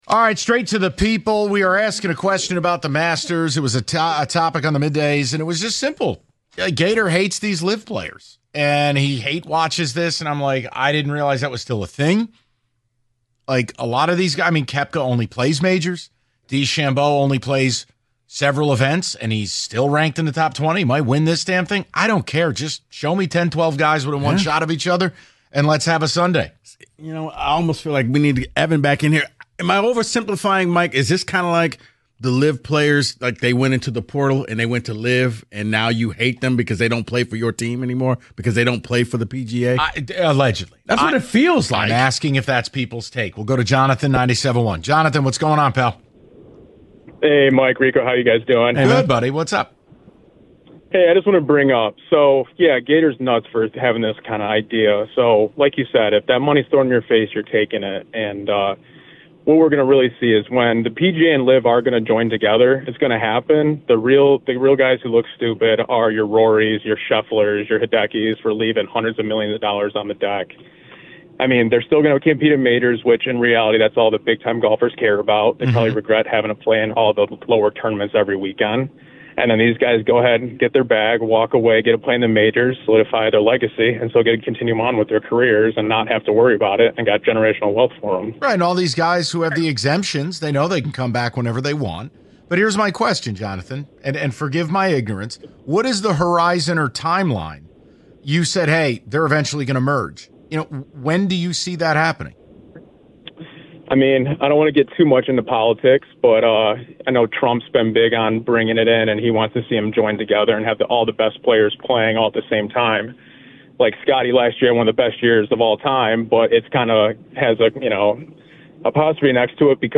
The guys take your calls on the LIV Golf question.